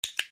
Dog Clicker - Sound Effect Button